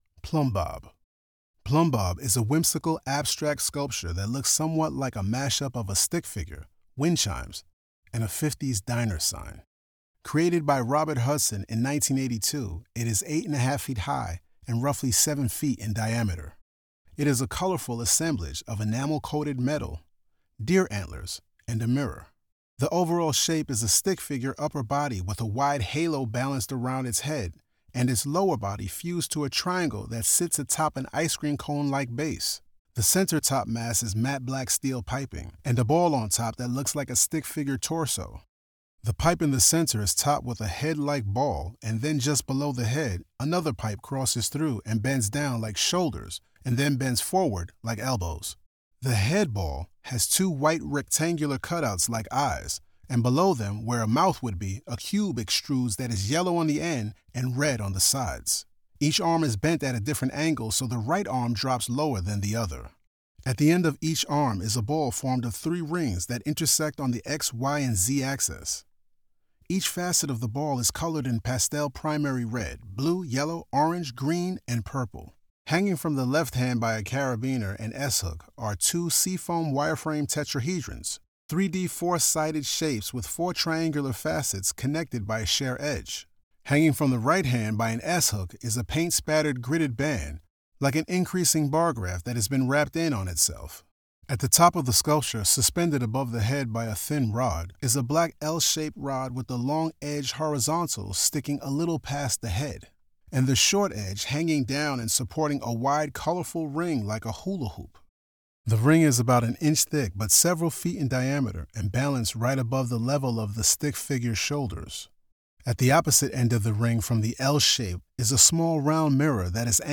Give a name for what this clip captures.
Audio Description (03:28)